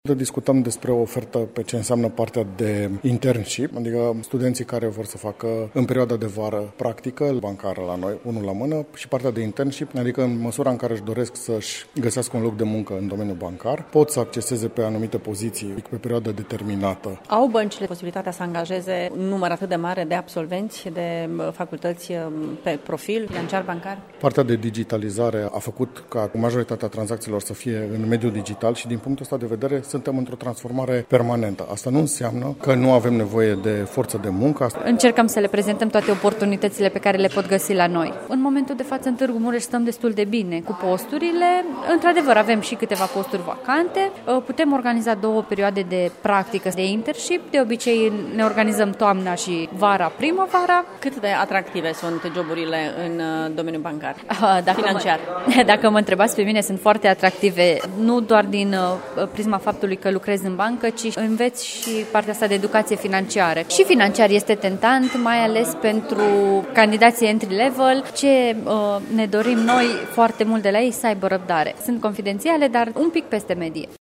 La Bursa din cadrul Campusului de pe str. Livezeni, pentru domeniile Economie și Drept, au fost prezenți astăzi reprezentanții celor mai importante filiale bancare de pe piață, alături de firme din domeniul comercial.